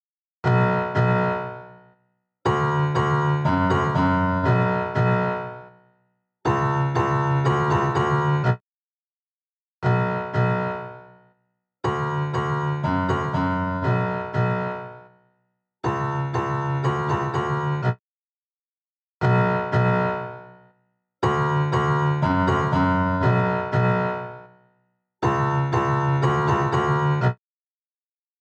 EChannel | Piano | Preset: Thick as a Brick